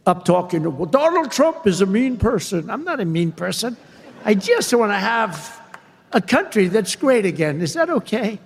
Trump referred to former President Barack Obama during a speech last week at the American Business Forum in Miami.